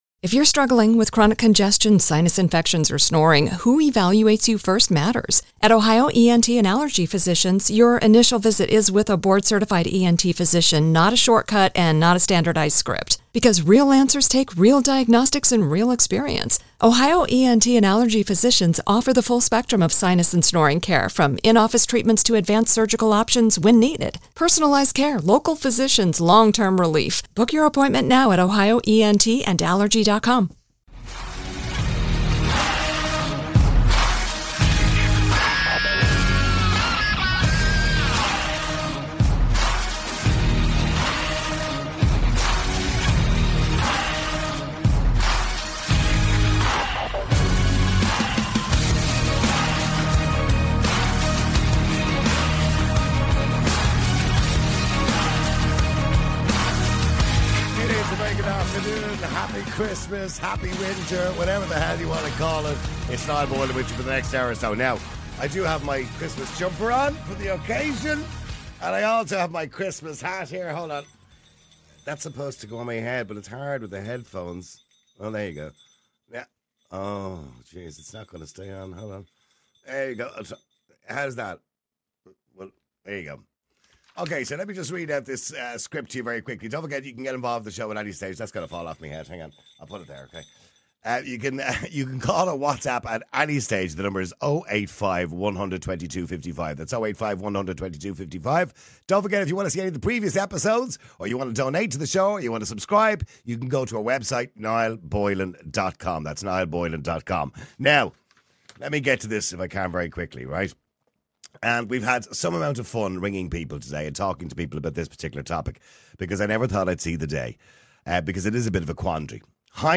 The lines are then opened to callers who share diverse perspectives on the matter. Some advocate for transparency, emphasizing that honesty is crucial in a relationship.